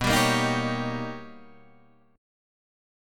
B+M9 chord